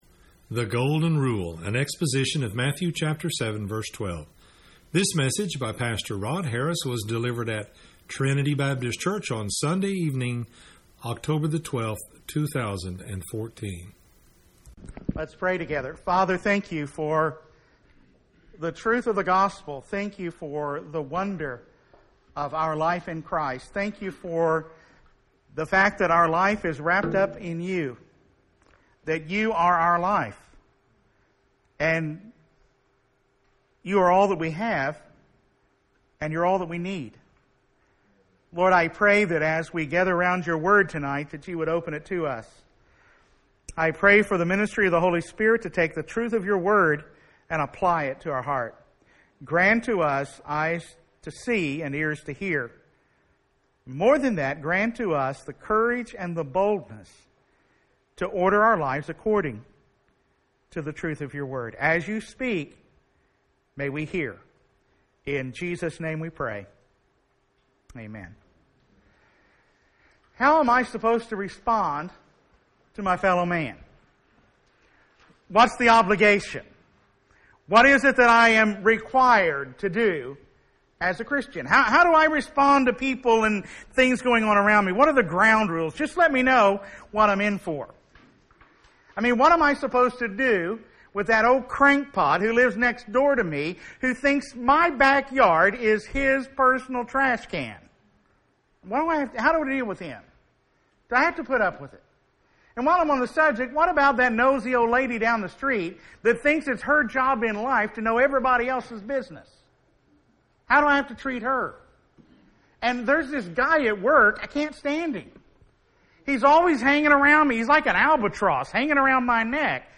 delivered at Trinity Baptist Church on Sunday morning